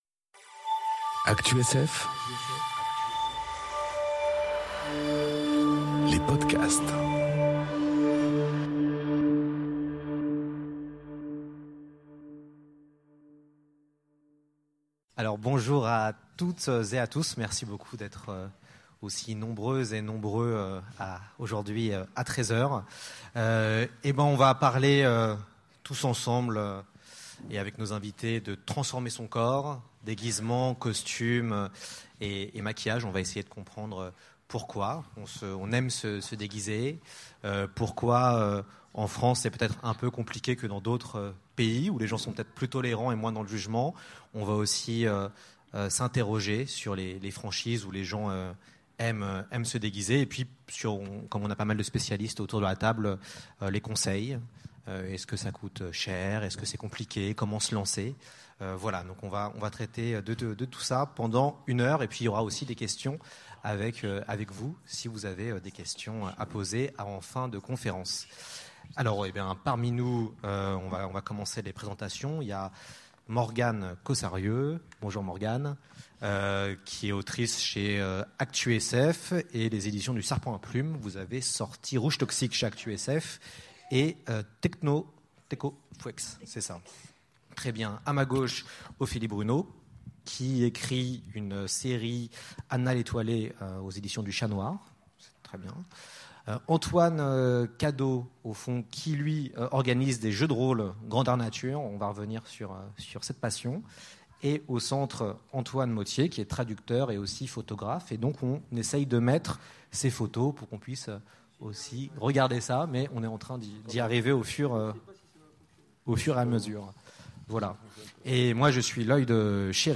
Conférence Transformer son corps : déguisements, costumes et maquillages enregistrée aux Utopiales 2018